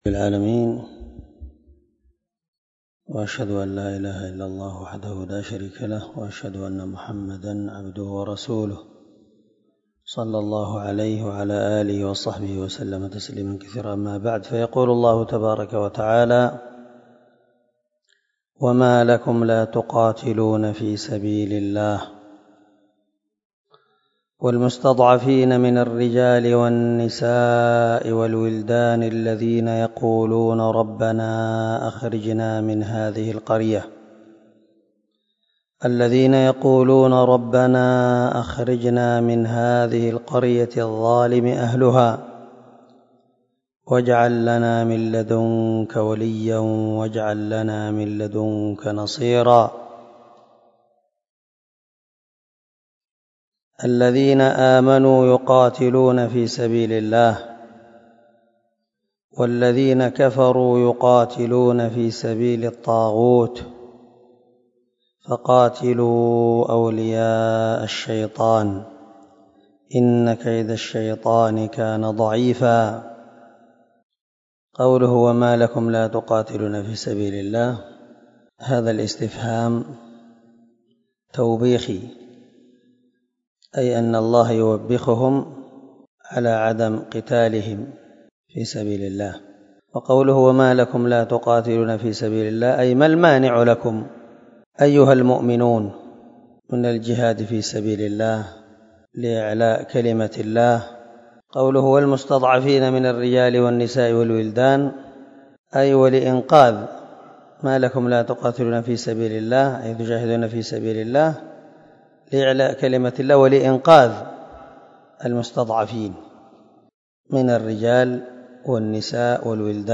281الدرس 49 تفسير آية ( 75 – 76 ) من سورة النساء من تفسير القران الكريم مع قراءة لتفسير السعدي